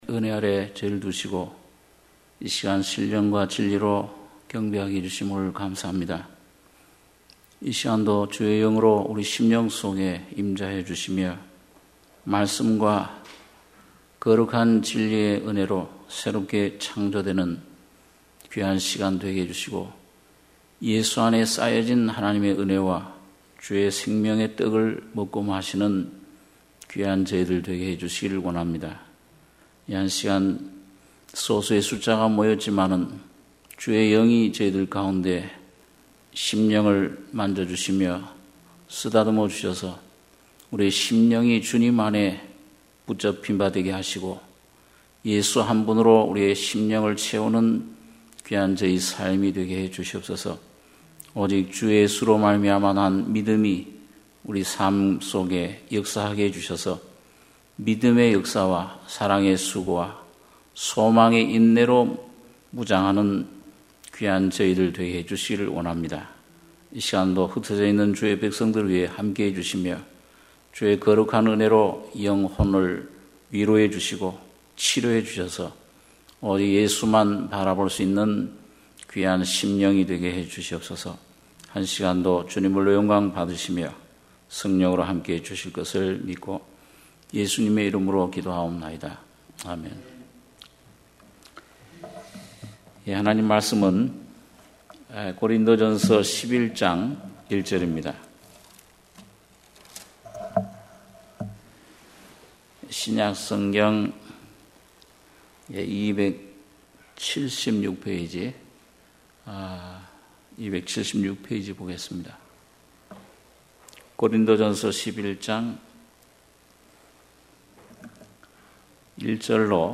수요예배 - 고린도전서11장 1~16절